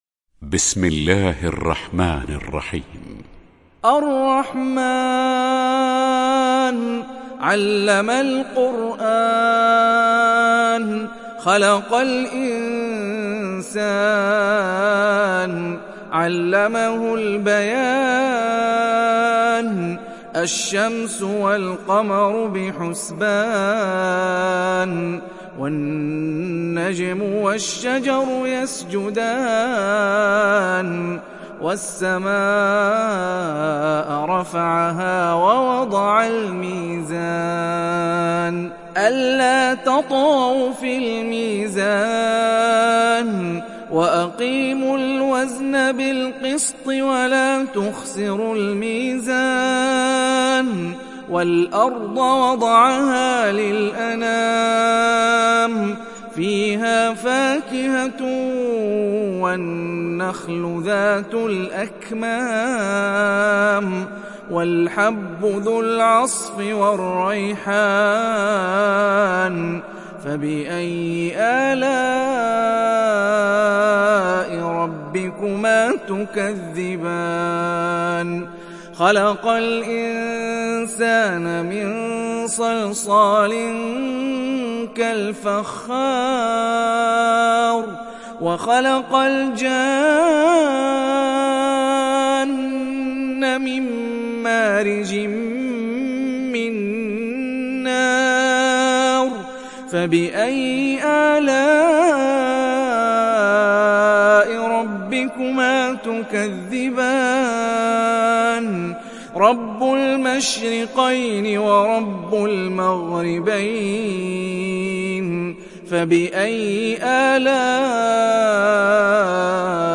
تحميل سورة الرحمن mp3 بصوت هاني الرفاعي برواية حفص عن عاصم, تحميل استماع القرآن الكريم على الجوال mp3 كاملا بروابط مباشرة وسريعة